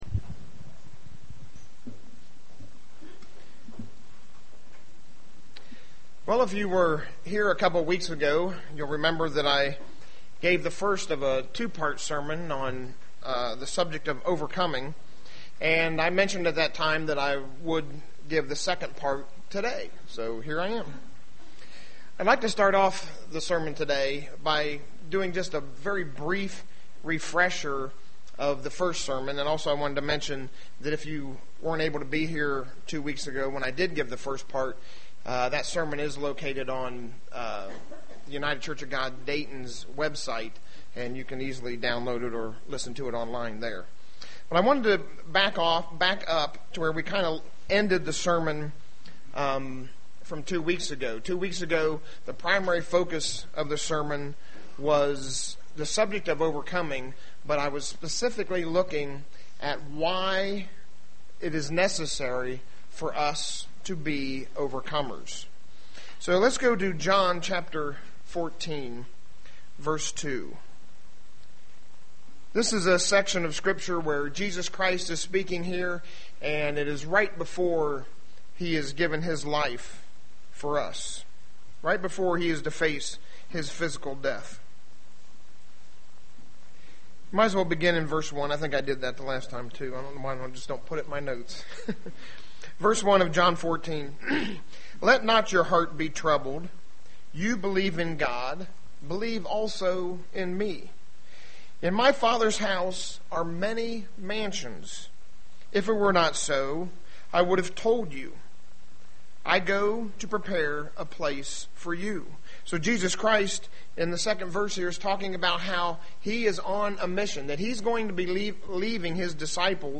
Print The path of overcoming UCG Sermon Studying the bible?
Given in Dayton, OH